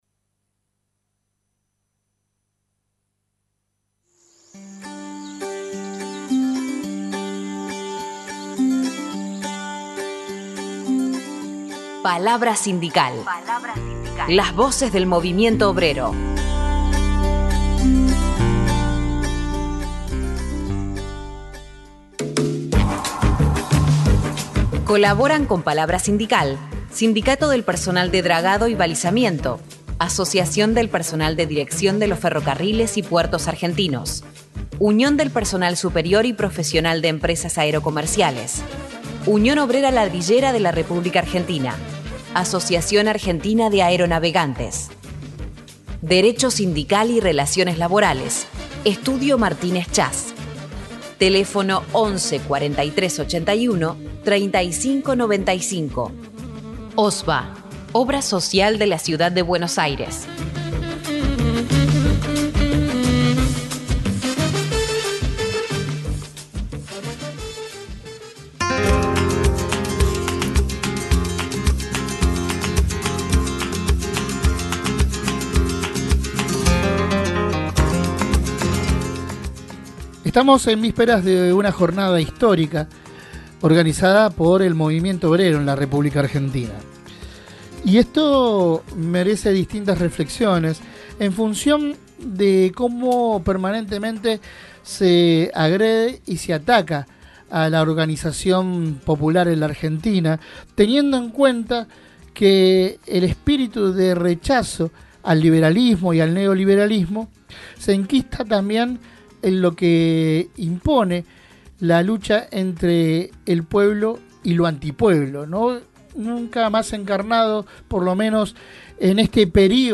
A continuación reproducimos la entrevista completa: https